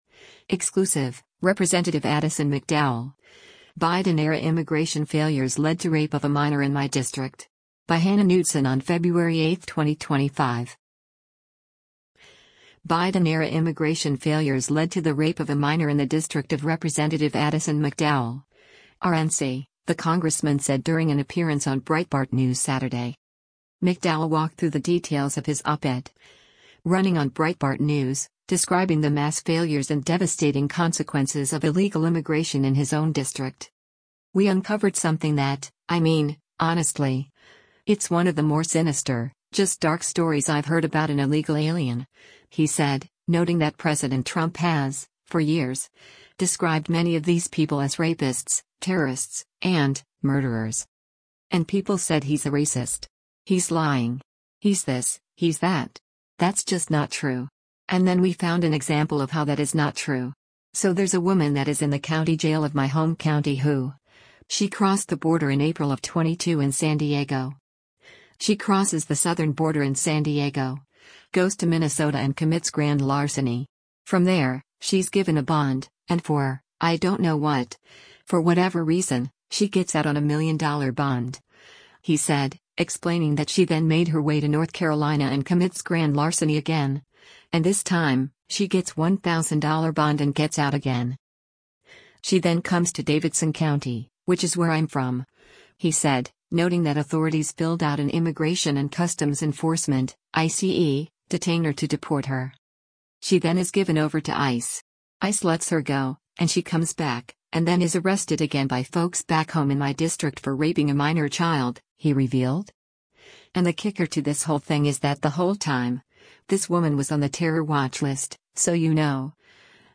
Biden-era immigration failures led to the rape of a minor in the district of Rep. Addison McDowell (R-NC), the congressman said during an appearance on Breitbart News Saturday.